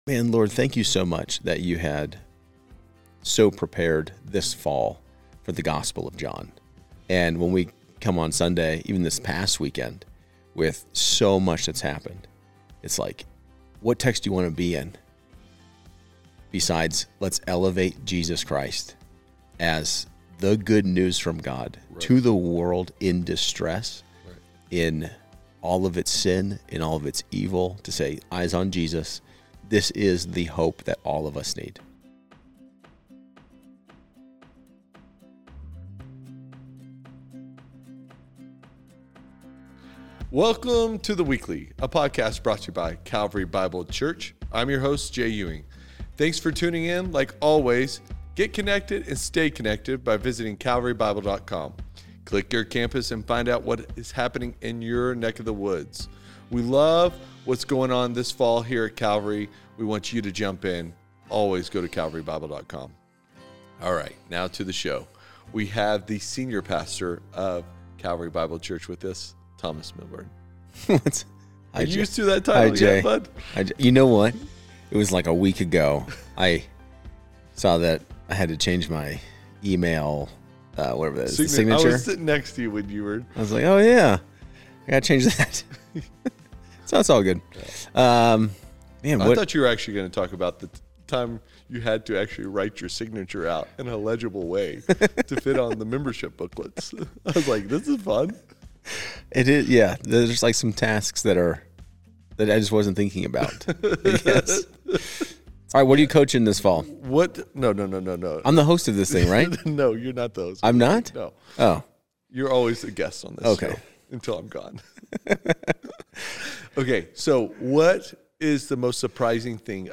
The episode closes with a reading of Psalm 23, offering a prayerful reminder of the comfort, guidance, and hope we have in Christ.